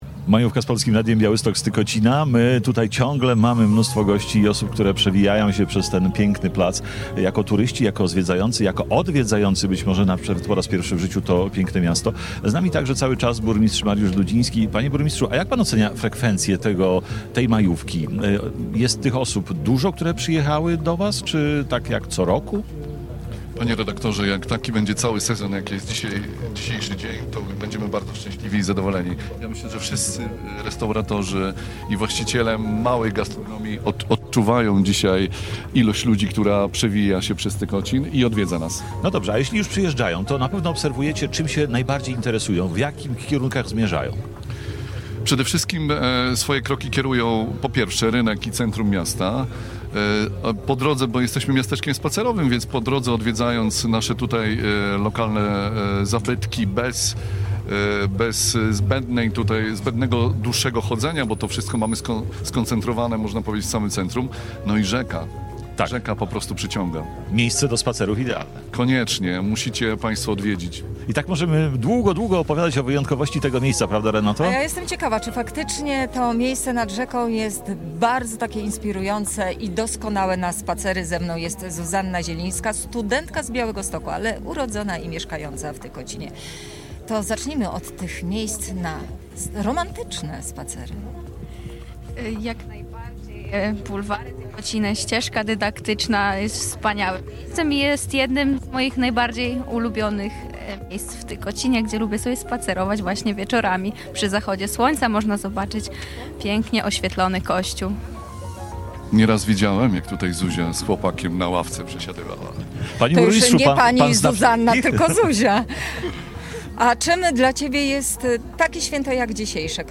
W sobotę, 3 maja właśnie z tego miasteczka program nadawało Polskie Radio Białystok.
Nasz program z Tykocina nadawaliśmy od 14:00 do 18.00. W naszym plenerowym studiu na Placu Czarnieckiego nasi słuchacze mogli podpatrzeć pracę dziennikarzy, ale także wziąć udział w wielu konkursach, które przygotowaliśmy.